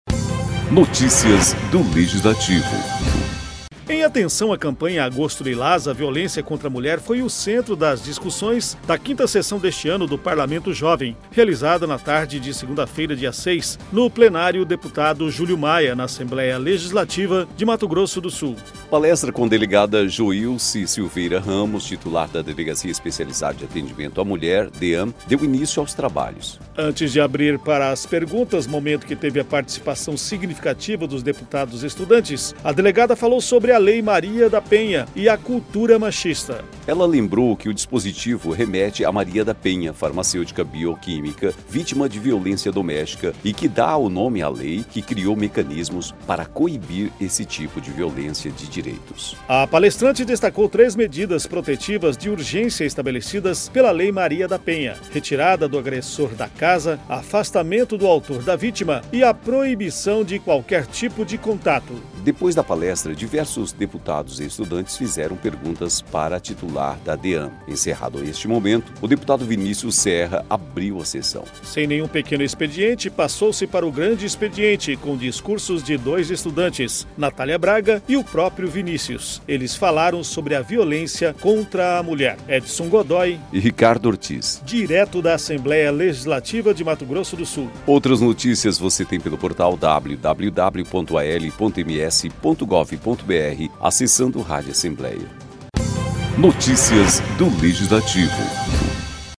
Discursos dos estudantes